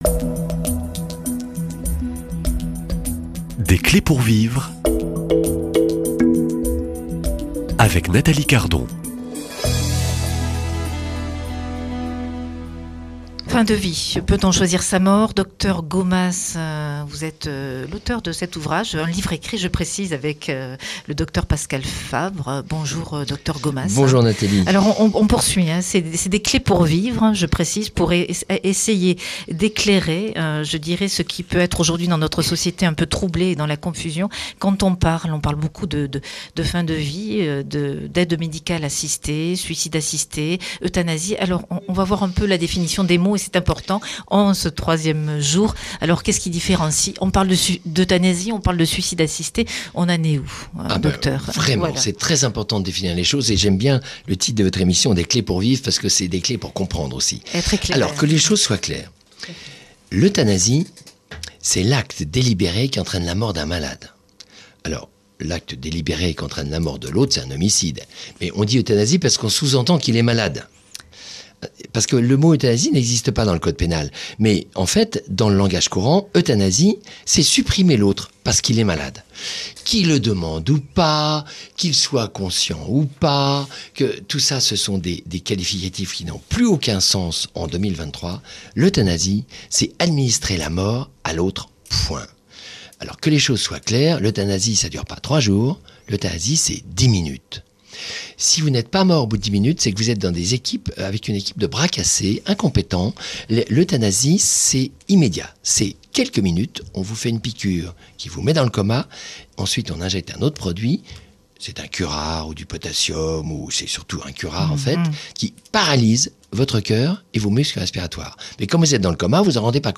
Une conférence salutaire pour éclairer les définitions et ne pas tout mélanger.